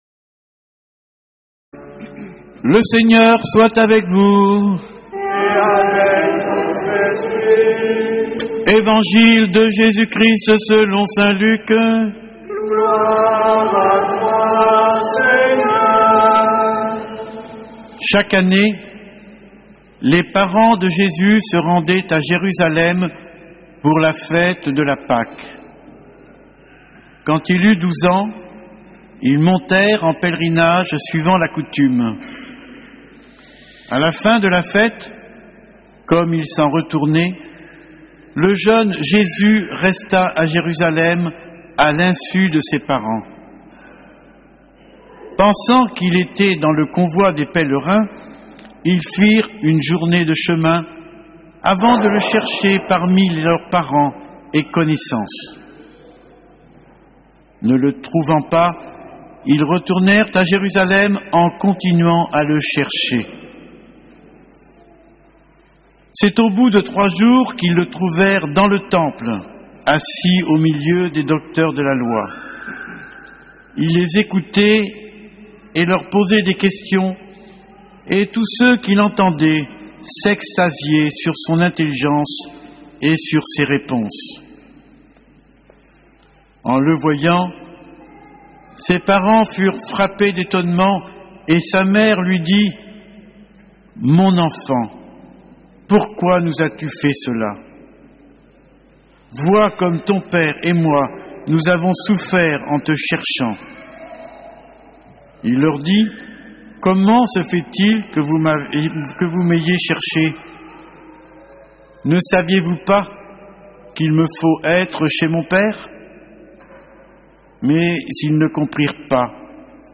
homelie-8.mp3